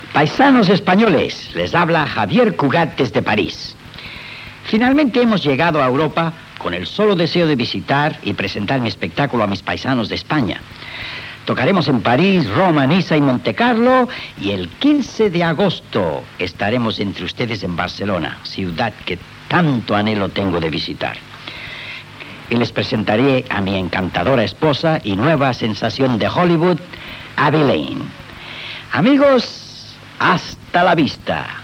El músic Xavier Cugat anuncia, des de París, que el 15 d’agost de 1954 actuarà a Barcelona amb la seva esposa i actriu cantant Abbe Lane.